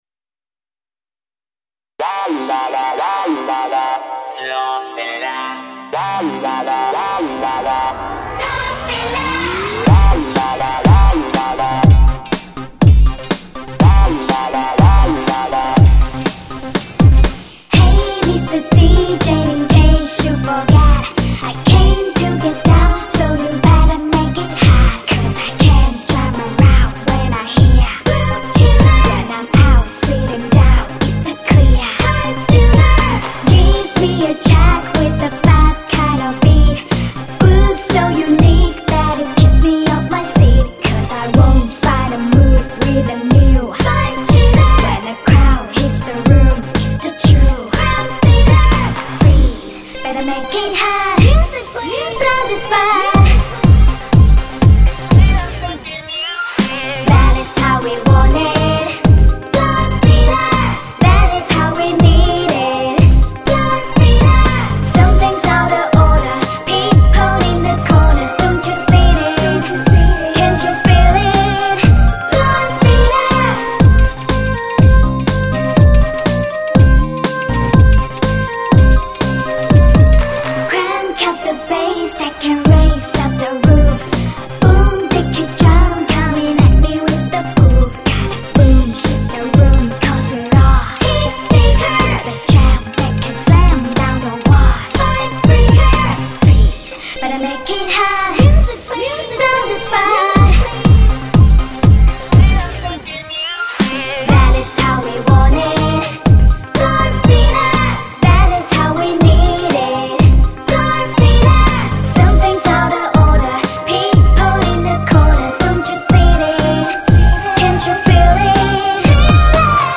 汇聚当今最流行的中文金曲，精挑细选欧洲最新颖的电音和节奏，